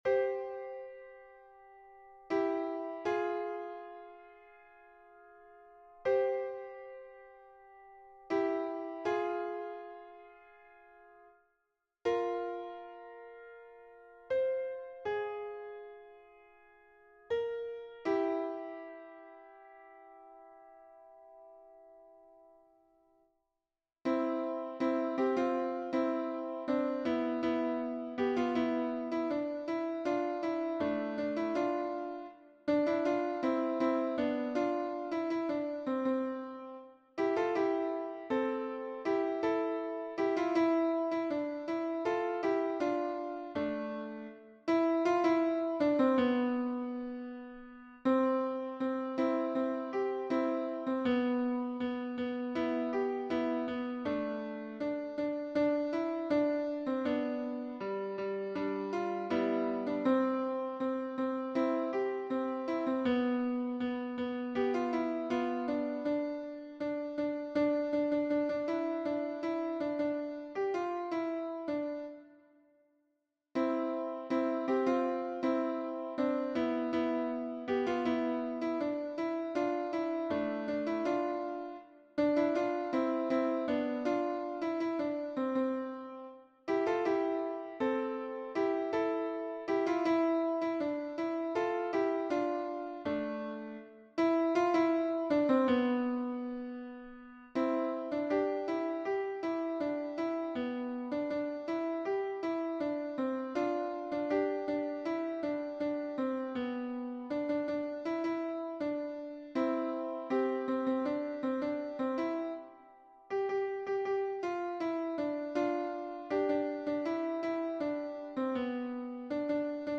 MP3 version piano (les audios peuvent être téléchargés)
Tutti (2 voix)